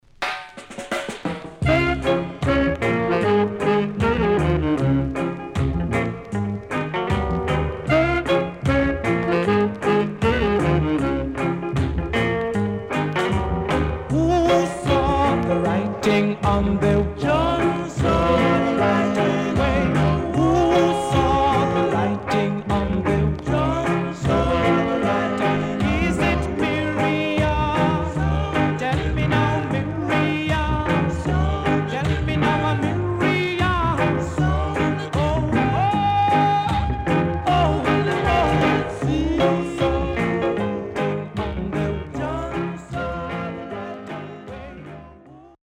HOME > Back Order [VINTAGE 7inch]  >  ROCKSTEADY
CONDITION SIDE A:VG(OK)〜VG+
SIDE A:所々チリノイズがあり、少しプチノイズ入ります。